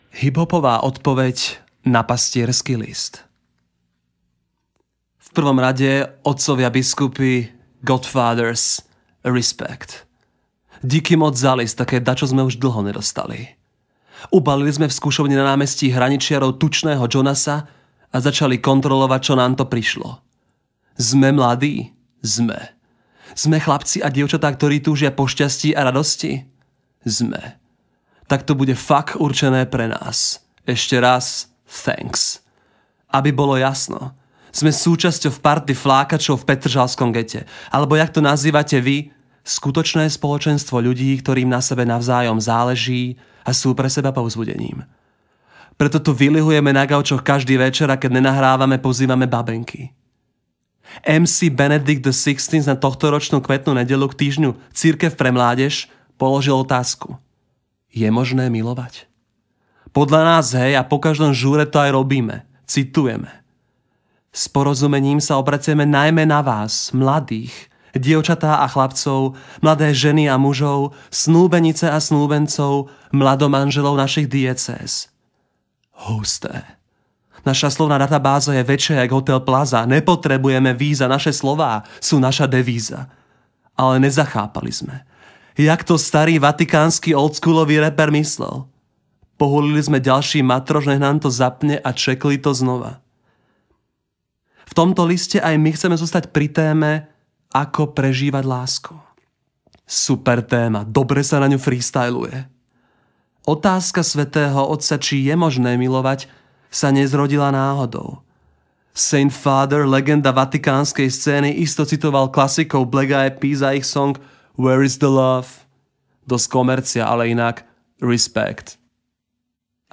hip-hopova-odpoved-na-pastiersky-list.mp3